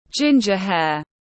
Ginger hair /ˈdʒɪn.dʒər heər/